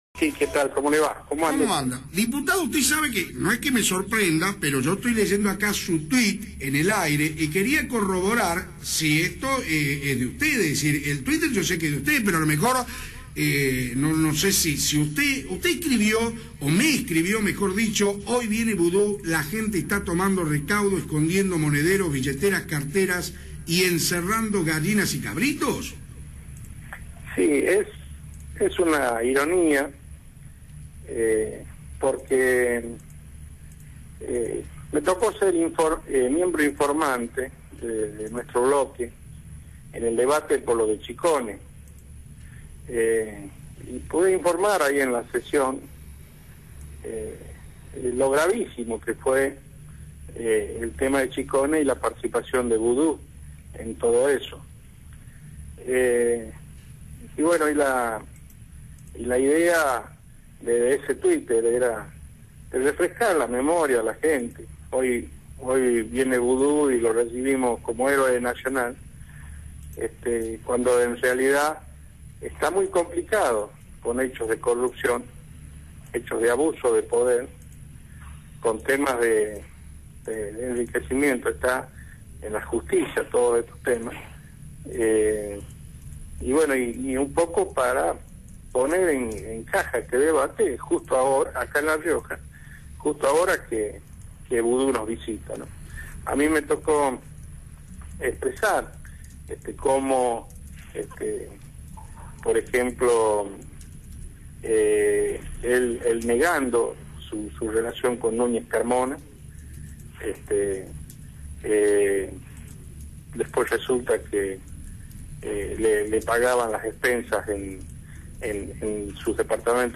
Luego Martínez confirmó por Radio Fénix ese mensaje por su cuenta de Twitter, al recordar las causas judiciales del vicepresidente de Cristina Fernández de Kirchner.
julio-martc3adnez-diputado-nacional-por-radio-fc3a9nix.mp3